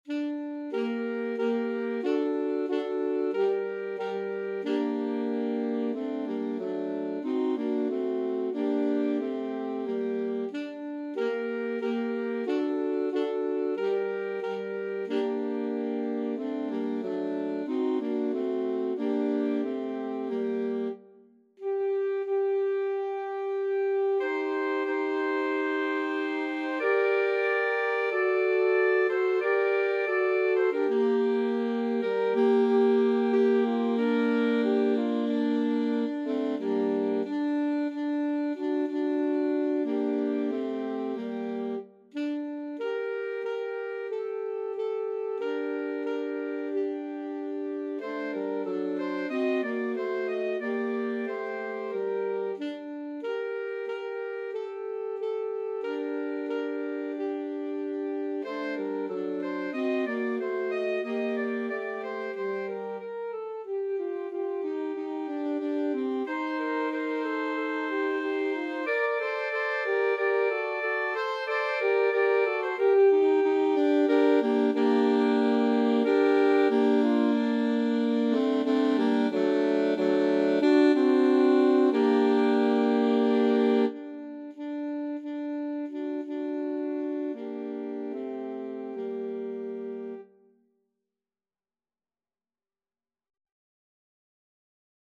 Alto Sax Trio version
Andante cantabile ( = c. 92)